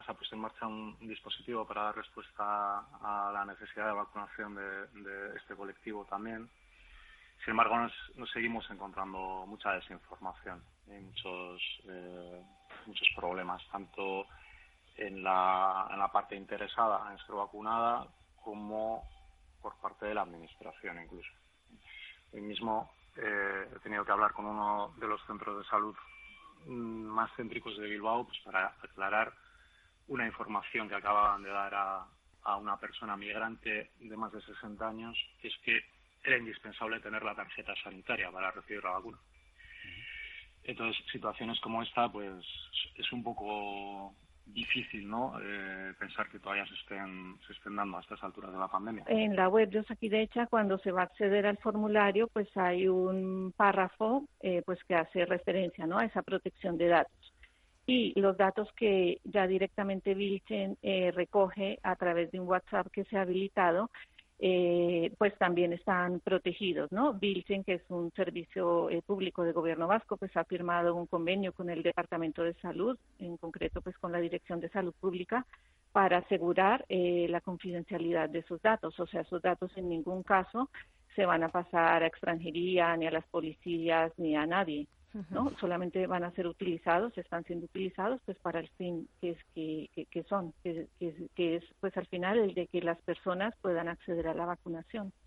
En directo